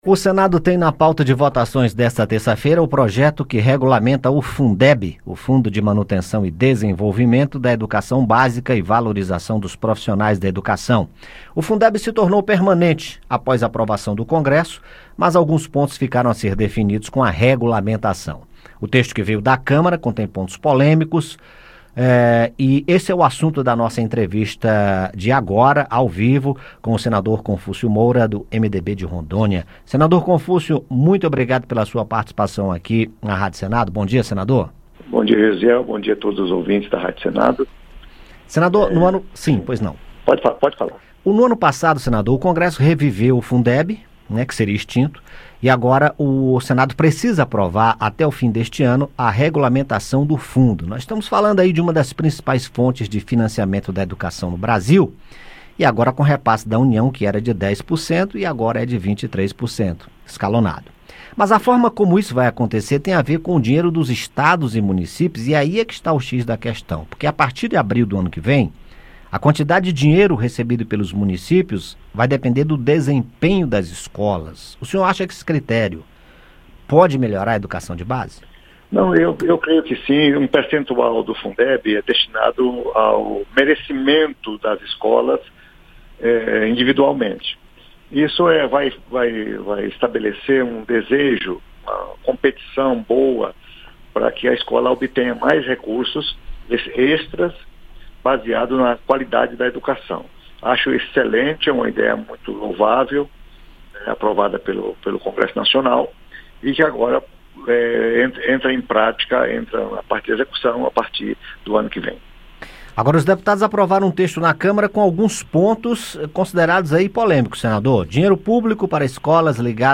Entrevista: Projeto que regulamenta o novo Fundeb